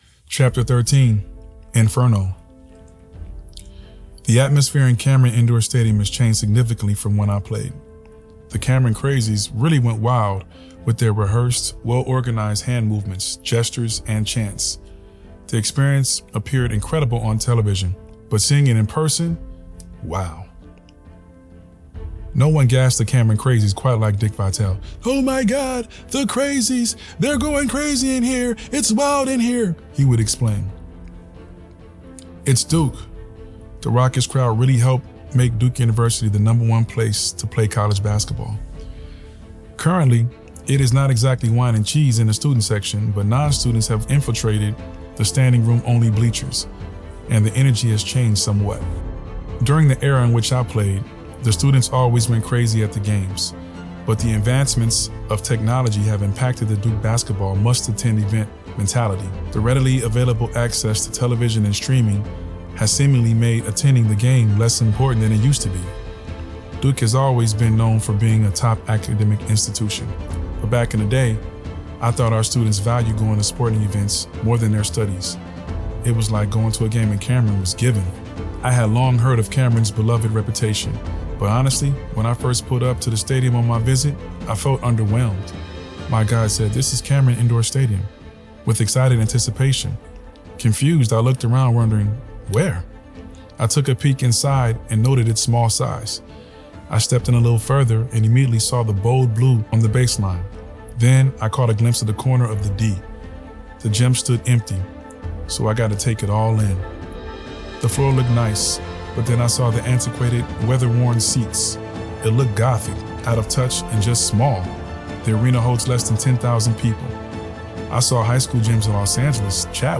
CYW-Chapter-13-With-Music.mp3